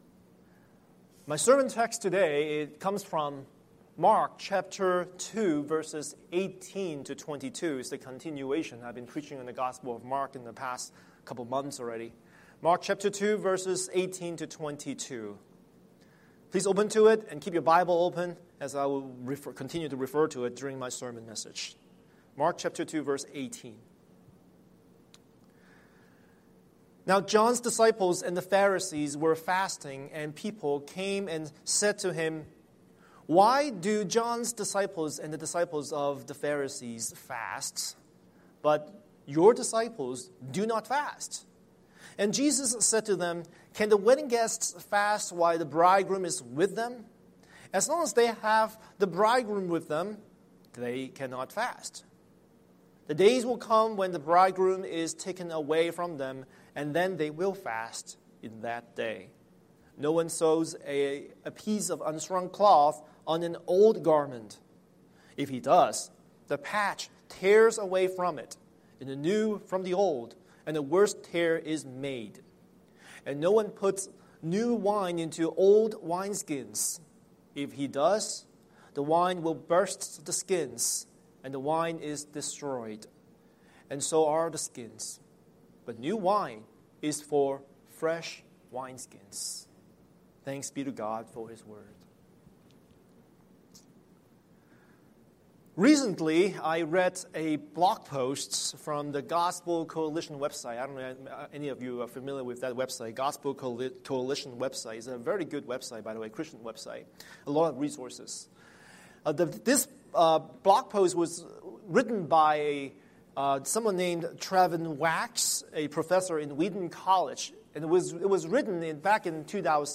Scripture: Mark 2:18-22 Series: Sunday Sermon